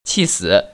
[qì//sĭ] 치쓰  ▶